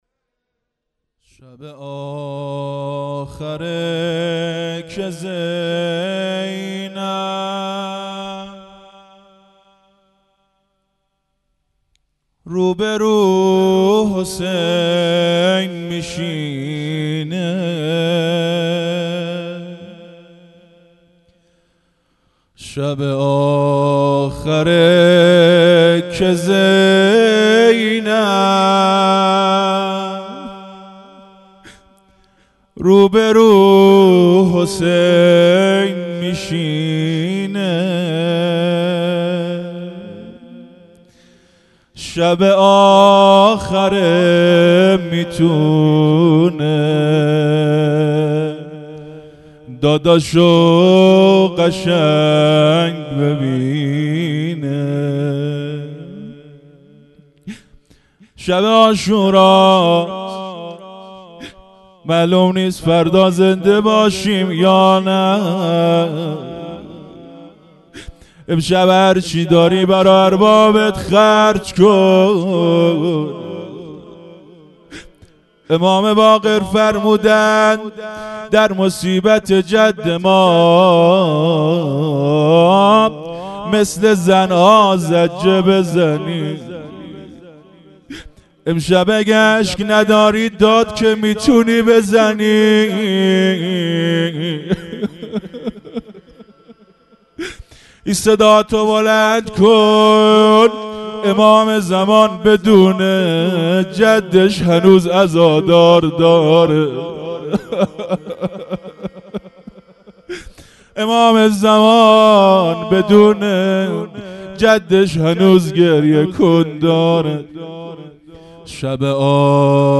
روضه|شب آخر که زینب روبرو حسین میشینه
محرم1442_شب دهم